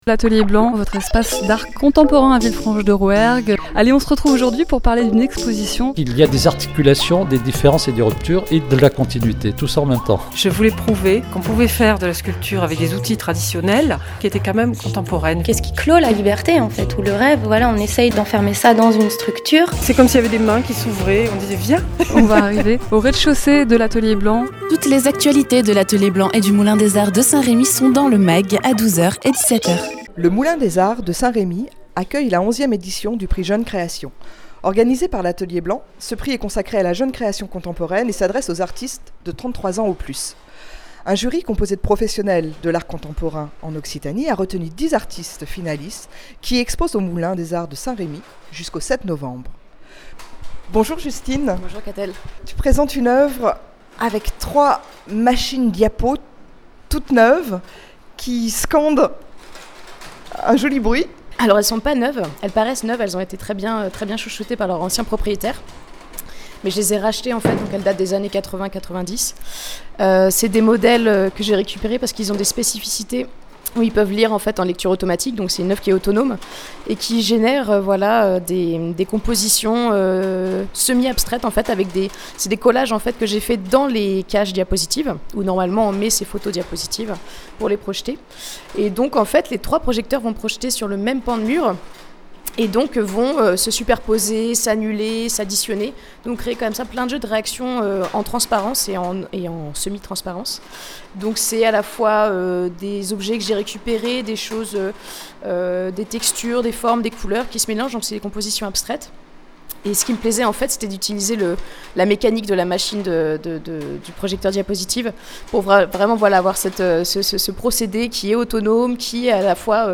Exposition visible jusqu’au 7 novembre. Dans ce reportage la médiatrice culturelle rencontre les 10 artistes finalistes.
Interviews